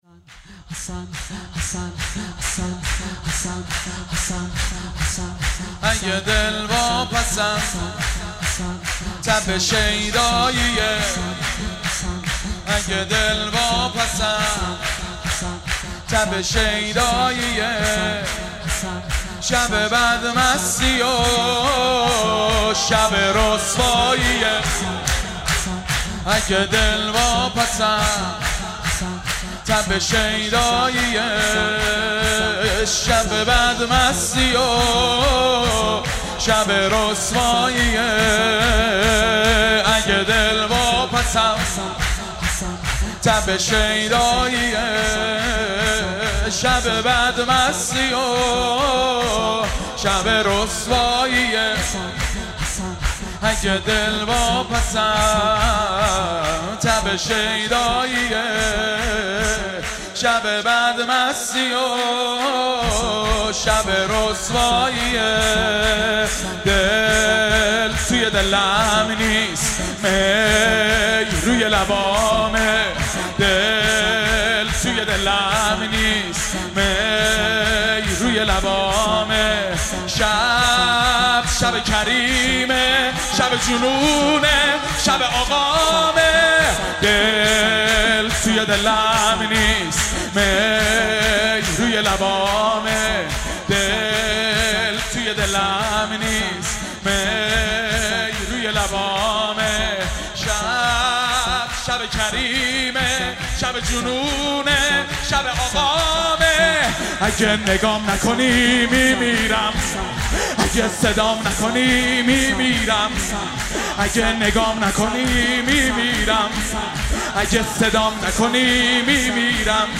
مراسم شب پانزدهم ماه رمضان با مداحی کربلایی حنیف طاهری درامامزاده اسماعیل(ع) چیذر برگزار گردید.